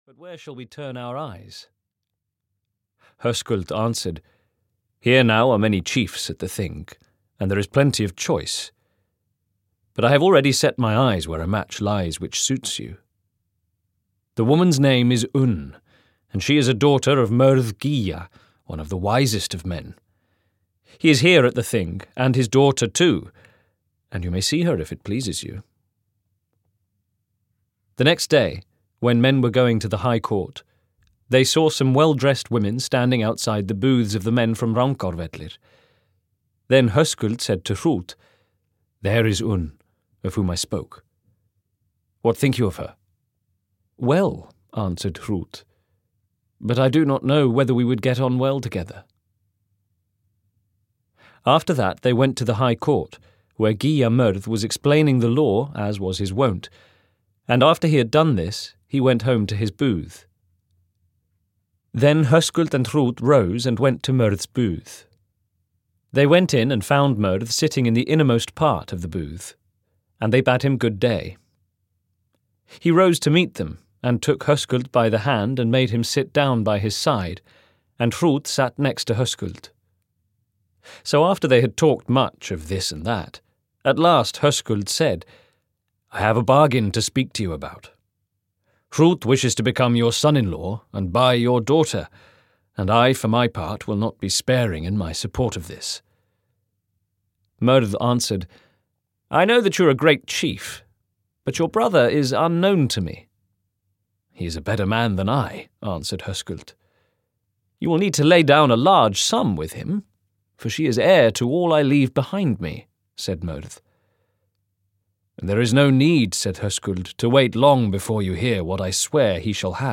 Njál's saga (EN) audiokniha
Ukázka z knihy